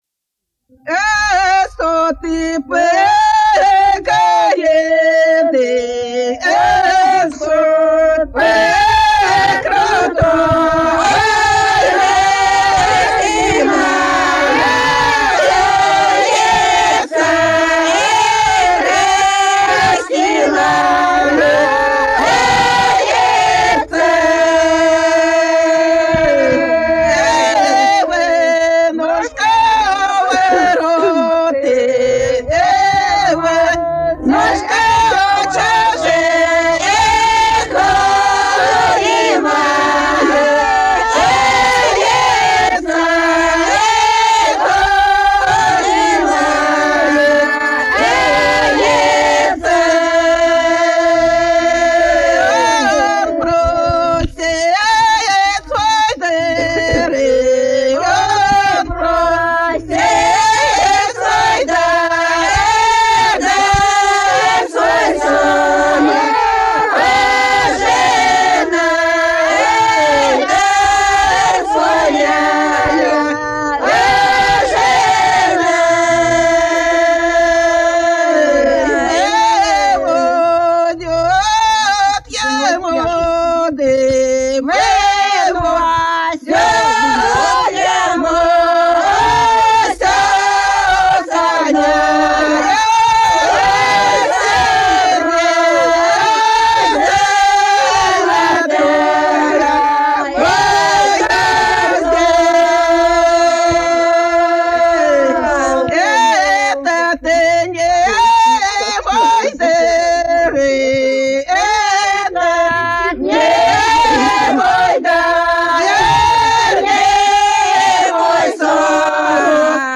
Народные песни Касимовского района Рязанской области «Осот по горе», свадебная.